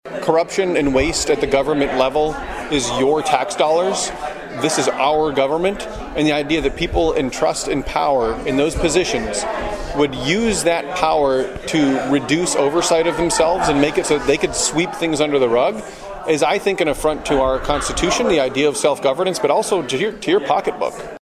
Sand, who is the only Democrat holding a statewide office, spoke to the Sioux City Rotary Club and later held a town hall in Onawa.